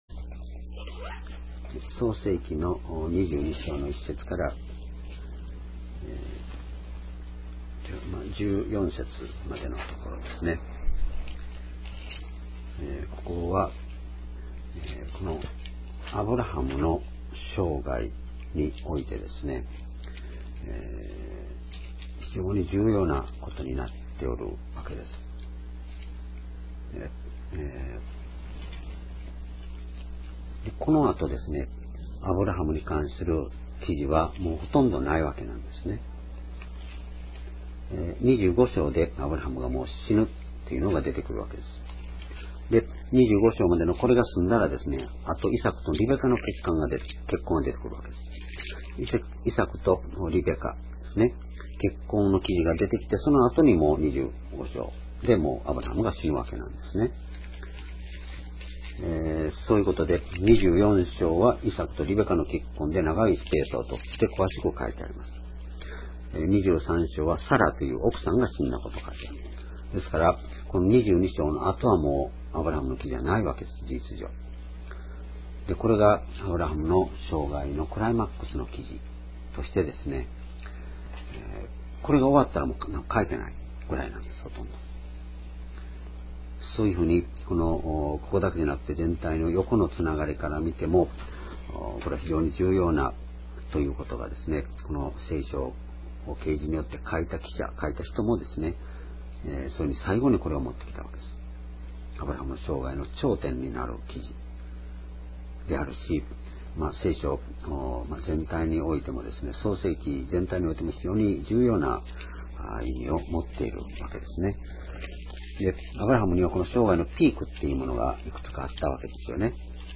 創世記講話